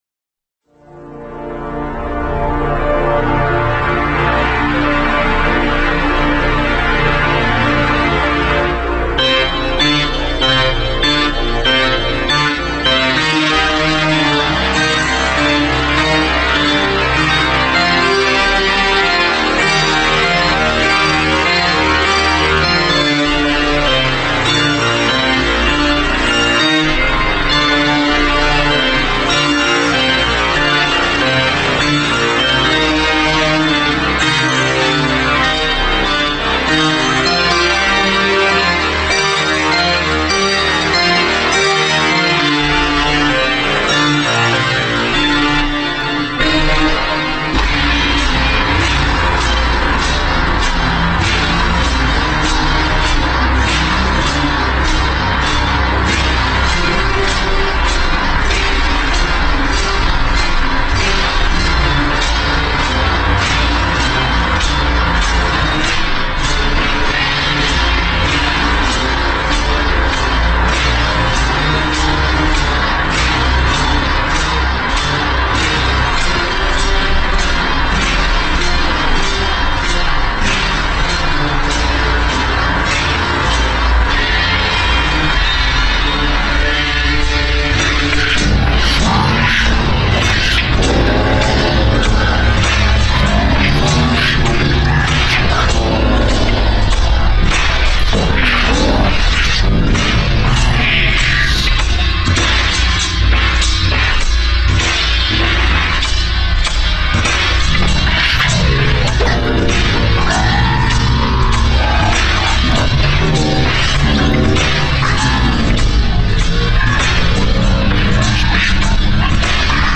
shitcore